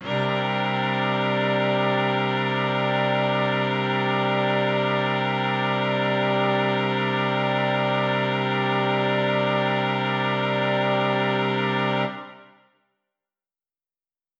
SO_KTron-Cello-C7:9.wav